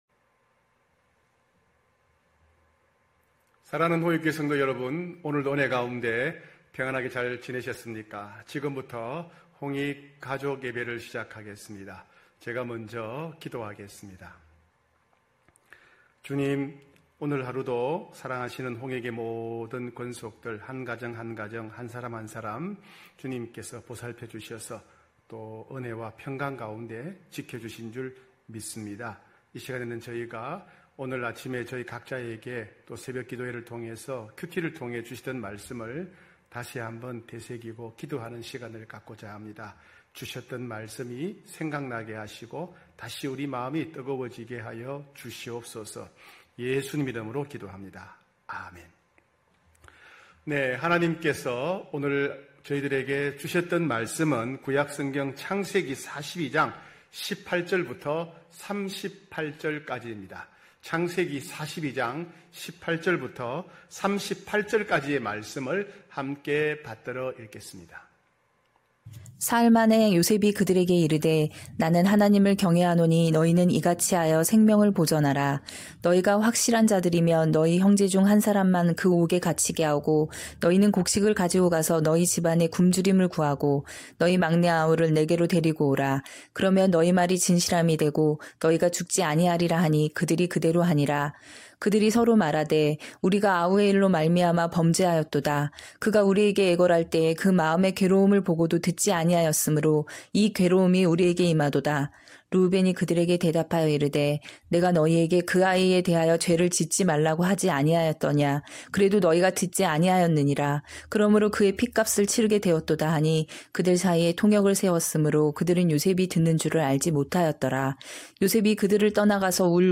9시홍익가족예배(9월10일).mp3